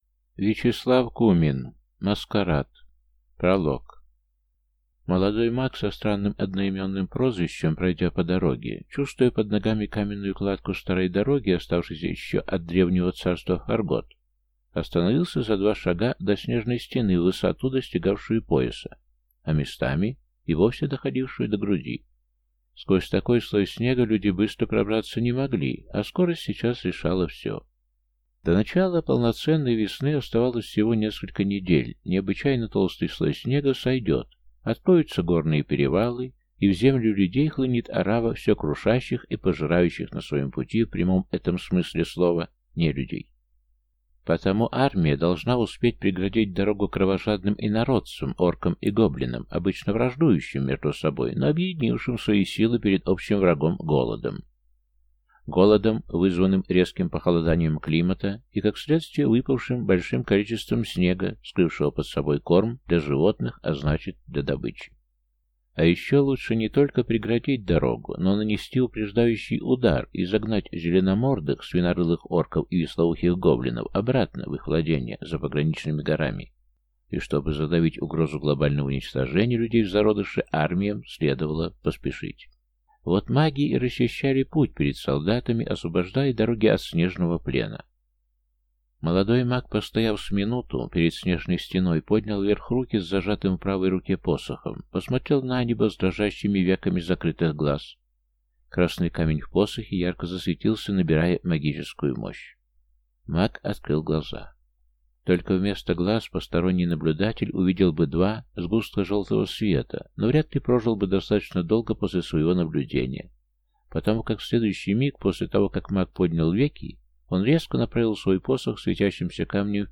Аудиокнига Маскарад | Библиотека аудиокниг